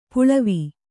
♪ puḷavi